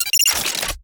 sounds / doors / futuristic / lock.ogg
lock.ogg